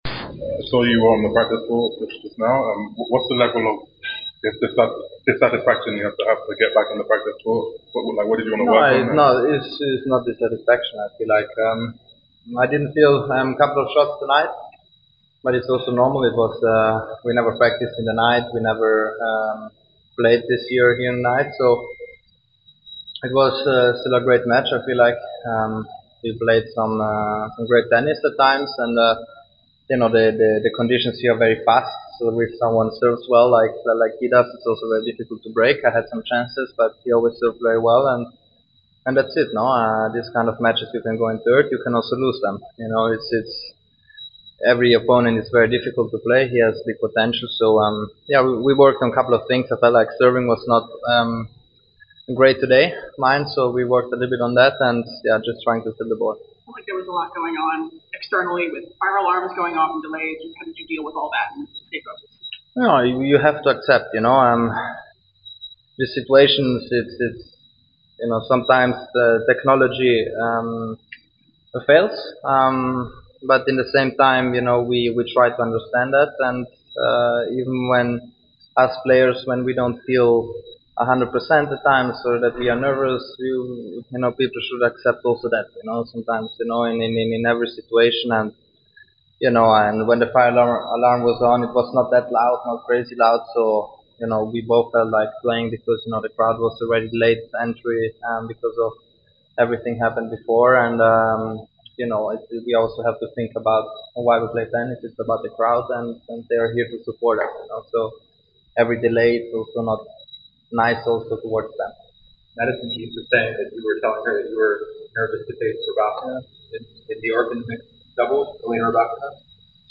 Jannik Sinner post-match interview after defeating Gabriel Diallo 6-2, 7-6 in the 3rd Round of the Cincinnati Open.